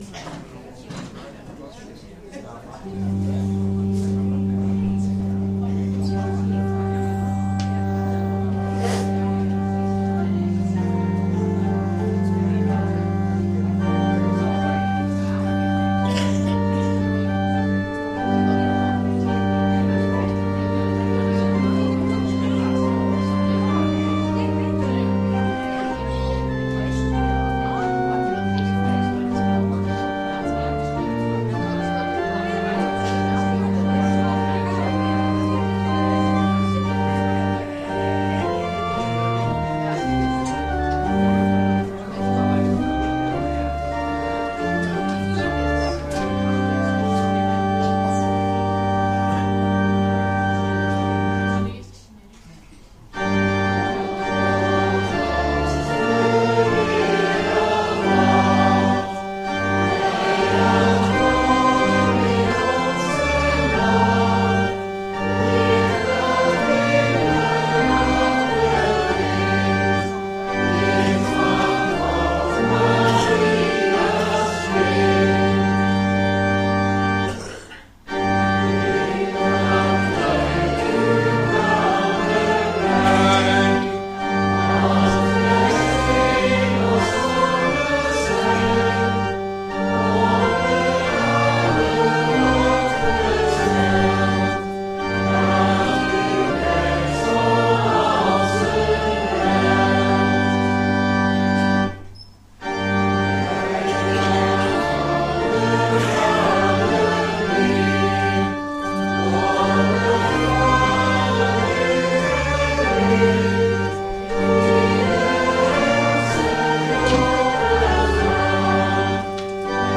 kerstnachtdienst